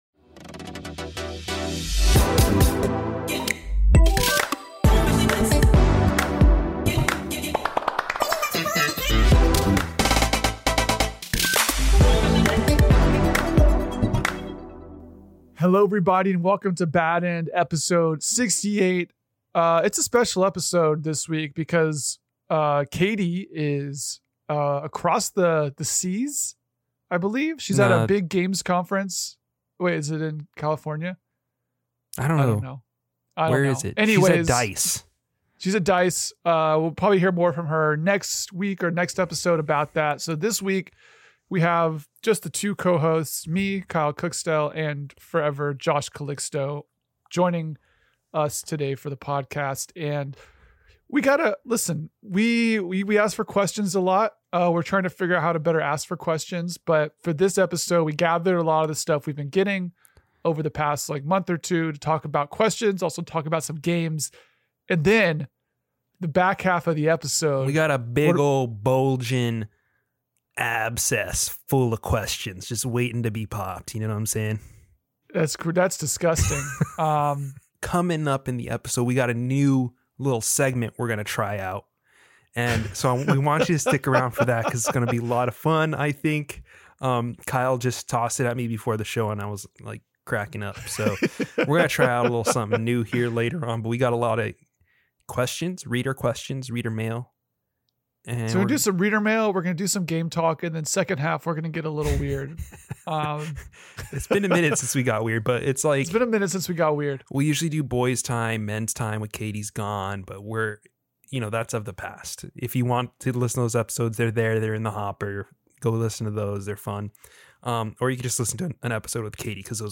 With one co-host gone this week, the remaining two end up shooting the breeze for like 2 hours straight in this one. We discuss the new Dragonball Z game, we rag on Ori and the Blind Forest, we talk about Slay The Spire and the concept of "perfunctory" game mechanics, and we answer a very, v...